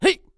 CK普通1.wav 0:00.00 0:00.39 CK普通1.wav WAV · 34 KB · 單聲道 (1ch) 下载文件 本站所有音效均采用 CC0 授权 ，可免费用于商业与个人项目，无需署名。
人声采集素材/男2刺客型/CK普通1.wav